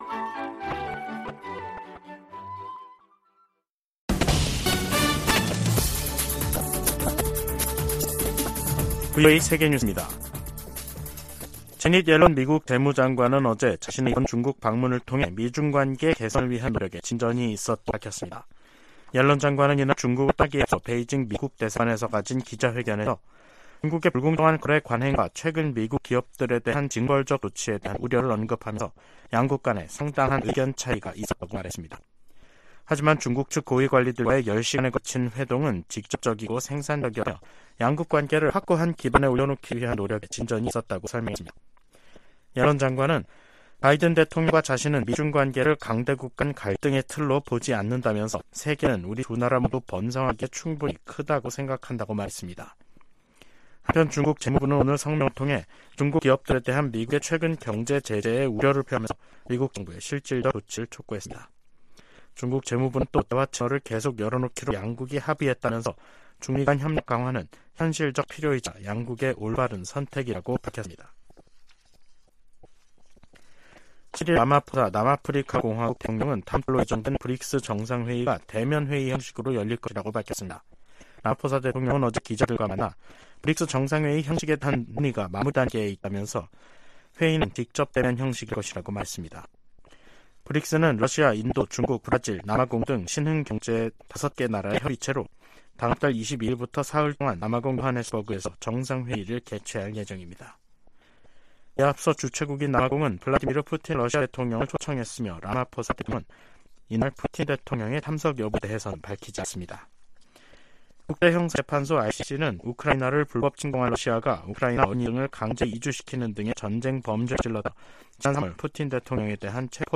VOA 한국어 간판 뉴스 프로그램 '뉴스 투데이', 2023년 7월 10일 2부 방송입니다. 미국과 한국이 오는 18일 서울에서 핵협의그룹 출범회의를 엽니다.